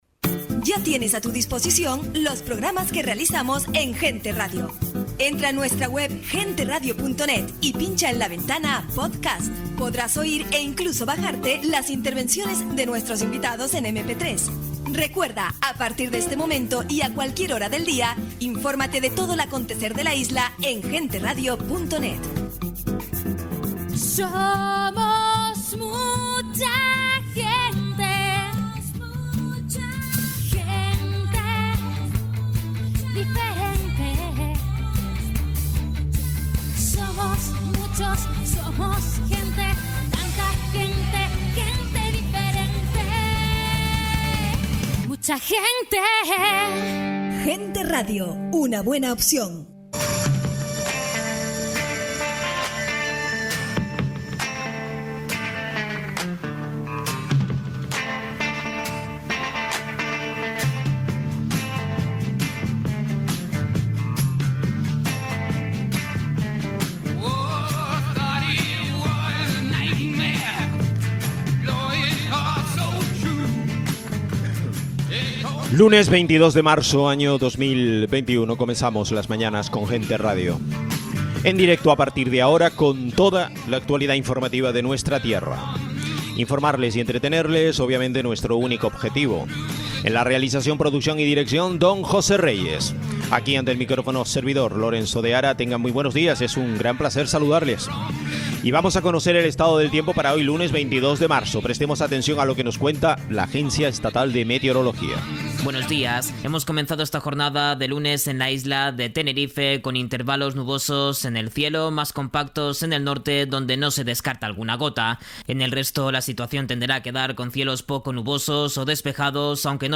Análisis y opinión
Tertulia